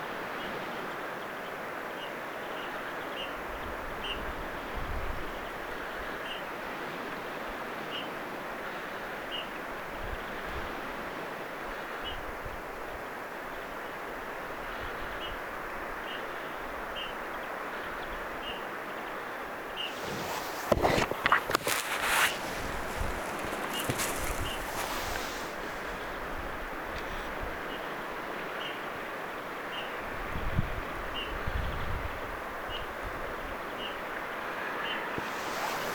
punatulkun ääniä
punatulkun_aantelya.mp3